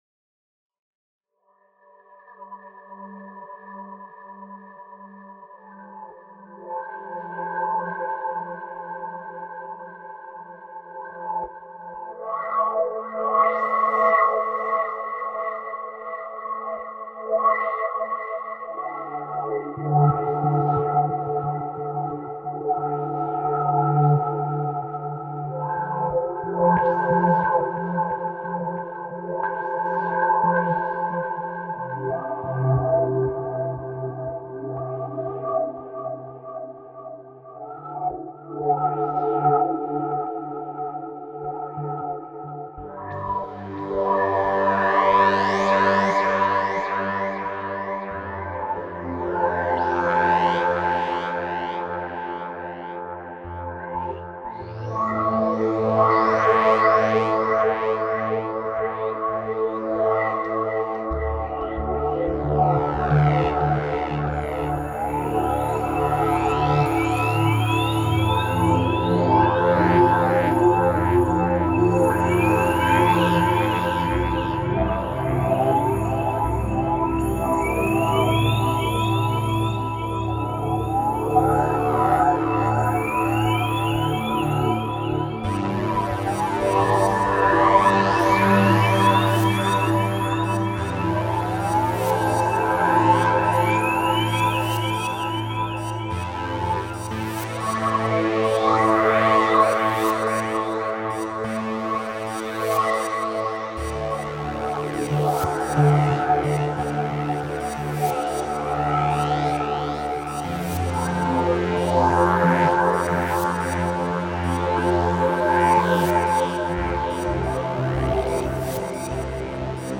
GENRE - Ambient - Space Oriented Synthesizer Instrumental